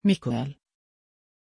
Aussprache von Miquel
pronunciation-miquel-sv.mp3